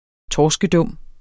Udtale [ ˈtɒːsgəˈdɔmˀ ]